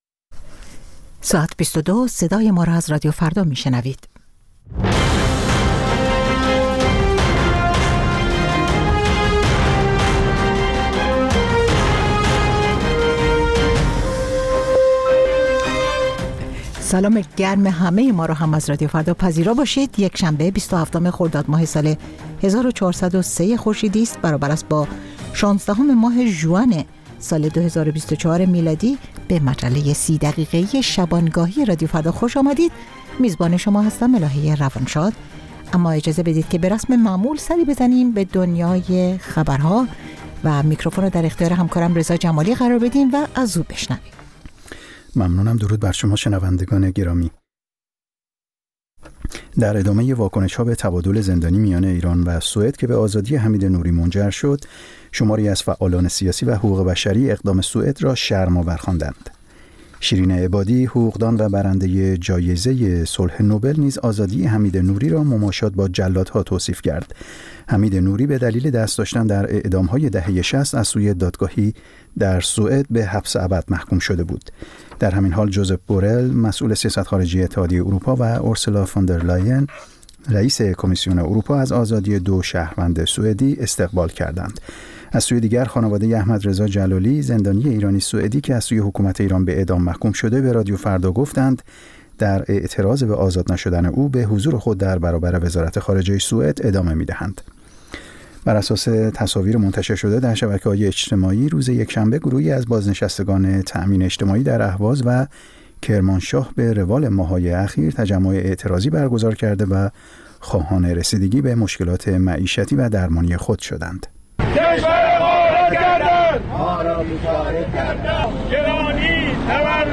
نیم ساعت با تازه‌ترین خبرها، گزارش‌های دست اول در باره آخرین تحولات جهان و ایران از گزارشگران رادیو فردا در چهارگوشه جهان، گفت‌وگوهای اختصاصی با چهره‌های خبرساز و کارشناسان.